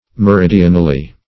meridionally - definition of meridionally - synonyms, pronunciation, spelling from Free Dictionary Search Result for " meridionally" : The Collaborative International Dictionary of English v.0.48: Meridionally \Me*rid"i*o*nal*ly\, adv. In the direction of the meridian.